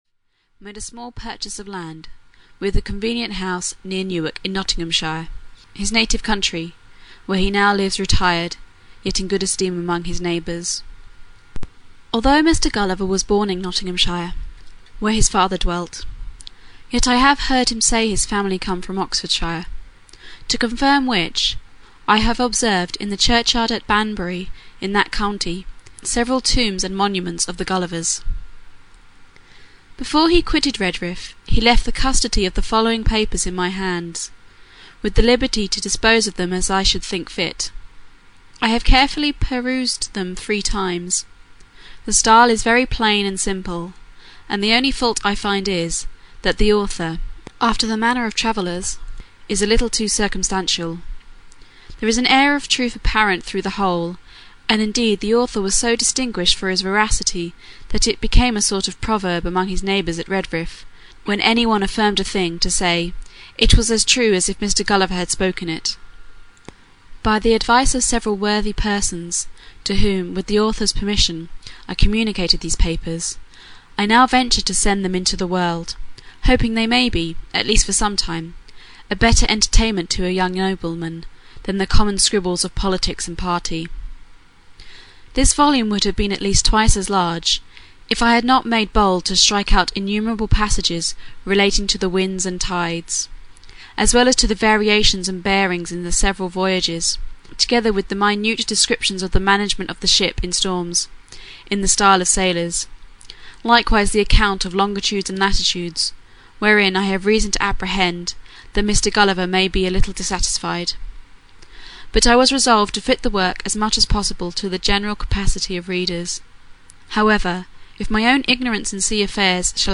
Gulliver s Travels (EN) audiokniha
Ukázka z knihy